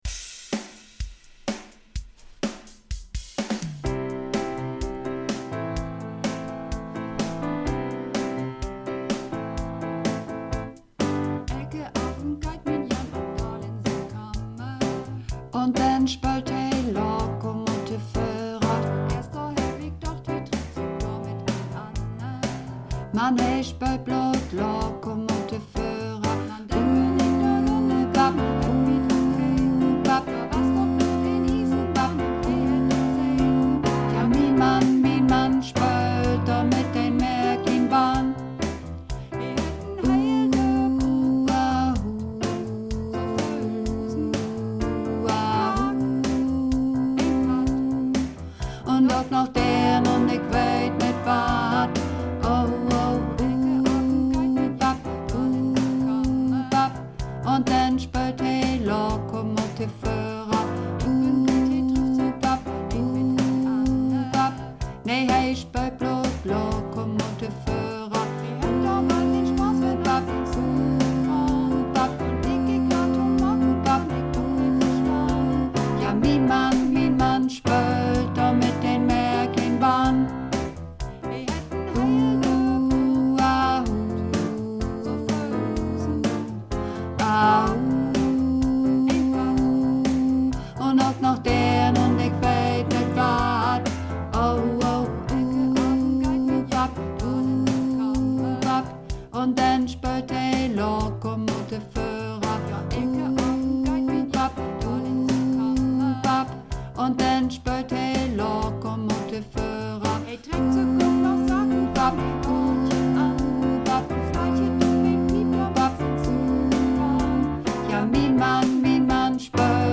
(Übungsaufnahmen - Übersichtsseite)
Runterladen (Mit rechter Maustaste anklicken, Menübefehl auswählen)   Lokomotivföhrer (Bass)